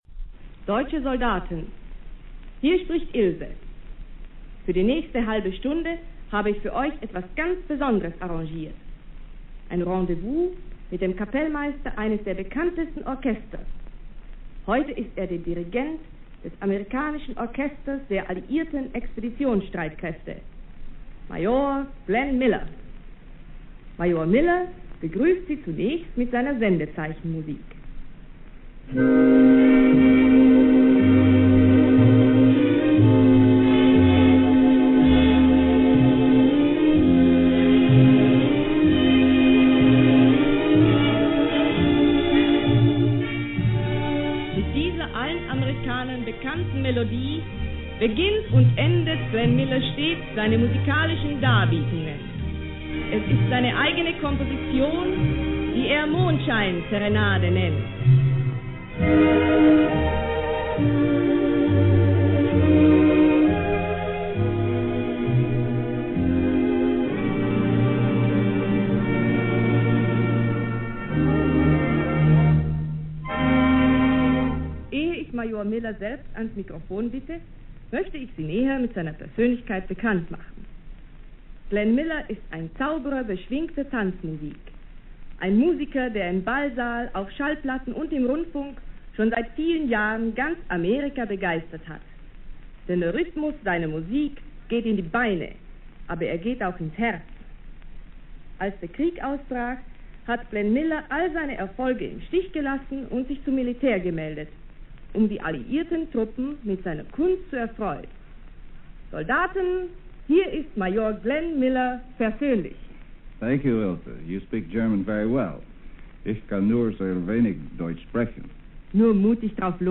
8 ноября 1944 года Германия услышала в радиоэфире женский голос, говоривший по-немецки:
И из репродукторов полились чарующие звуки.
Мужской голос заговорил на смеси английского и немецкого: